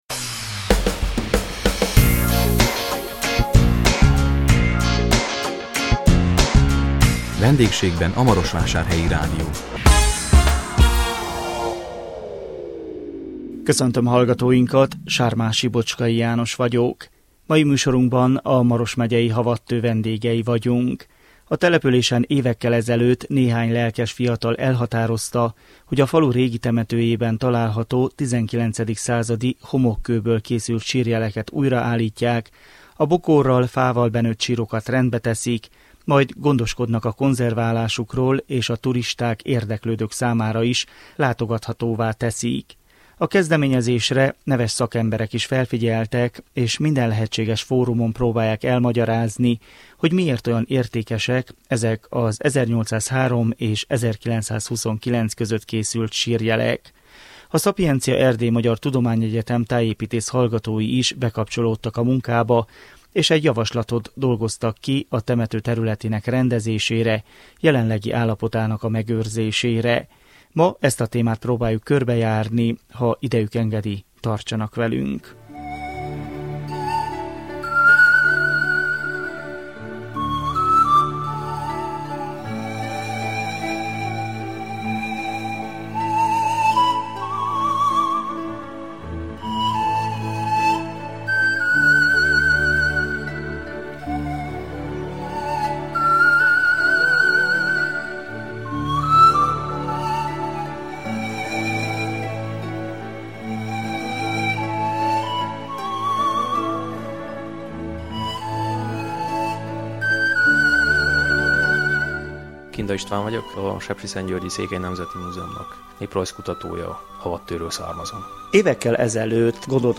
A 2016 december 29-én jelentkező Vendégségben a Marosvásárhelyi Rádió című műsorunkkal a Maros megyei Havadtő vendégei voltunk. A településen évekkel ezelőtt néhány lelkes fiatal elhatározta, hogy a falu régi temetőjében található XIX. századi homokkőből készült sírjeleket újraállítják, a bokorral, fával benőtt sírokat rendbe teszik, majd gondoskodnak a konzerválásukról és a turisták, érdeklődők számára is látogathatóvá teszik.